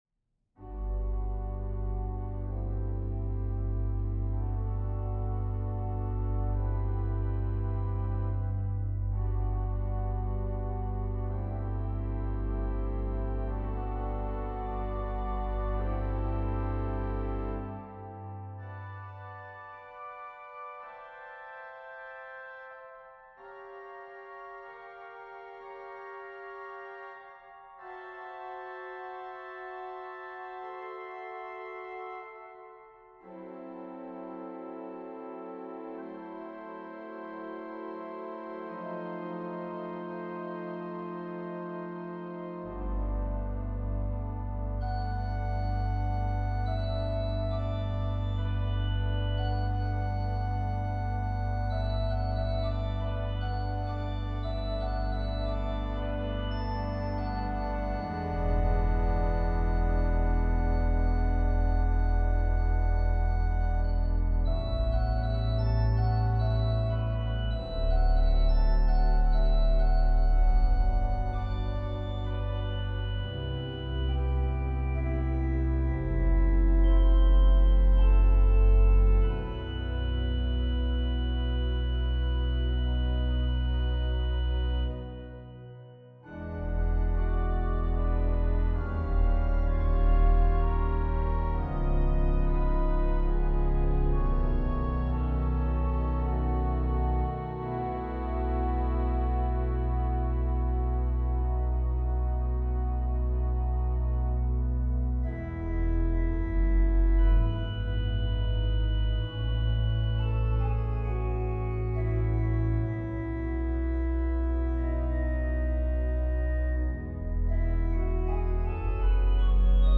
Voicing Organ